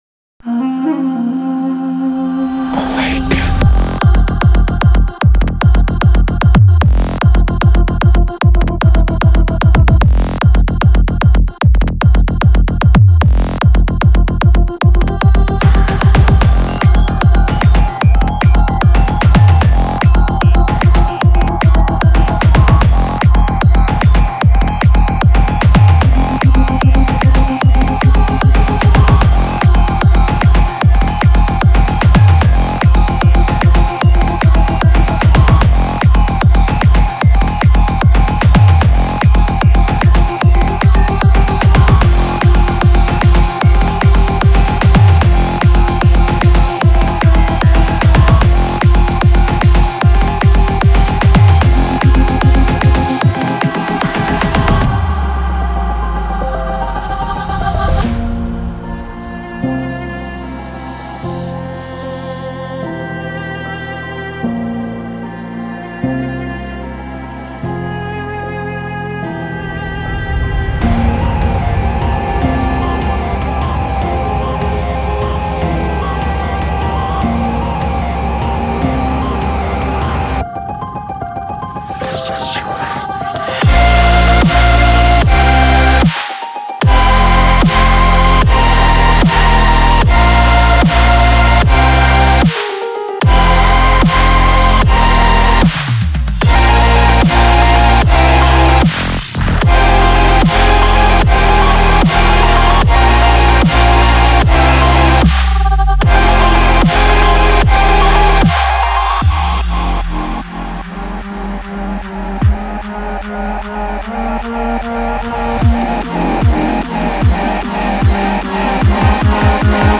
Aggressive, Anxious, Chaotic, Dark, Dramatic, Epic
Instrumental
Spooky and groovy and rich